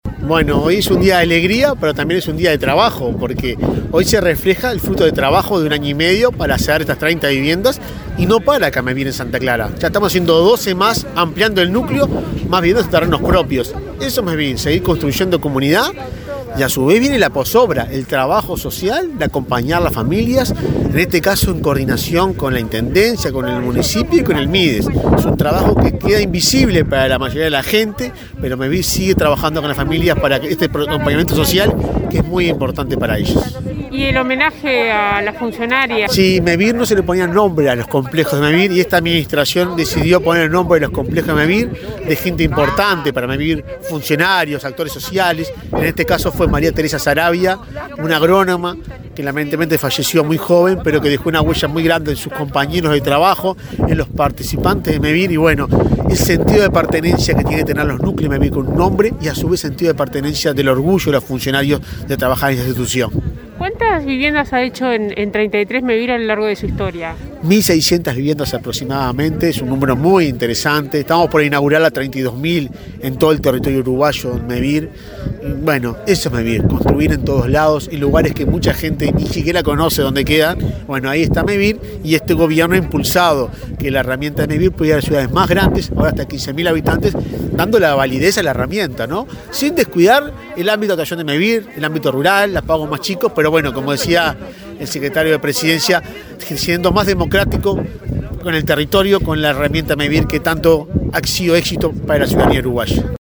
Entrevista al presidente de Mevir, Juan Pablo Delgado, en inauguración de viviendas en Treinta y Tres